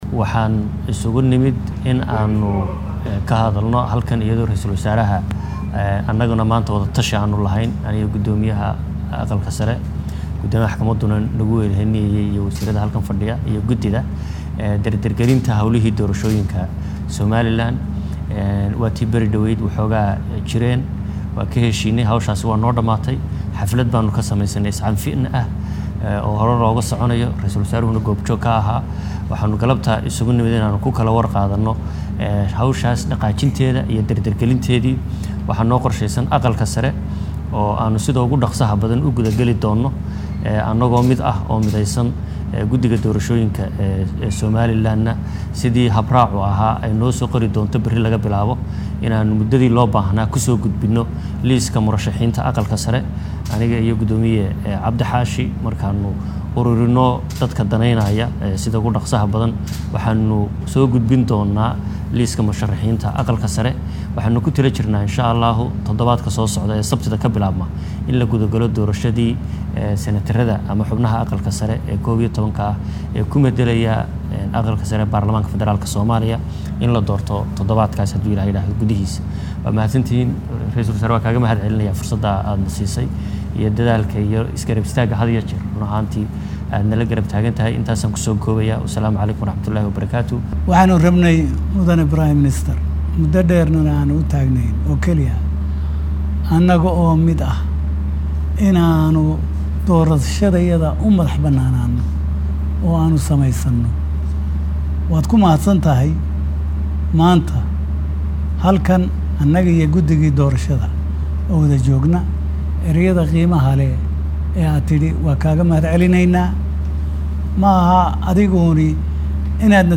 Ka dib markii uu shirka soo idlaaday ayaa waxaa warbaahinta la hadlay ra’iisul wasaare ku xigeenka soomaaliya Mahdi Maxamad Guuleed Khadar iyo guddoomiyaha aqalka sare ee Soomaaliya Cabdi Xaashi Cabdullahi.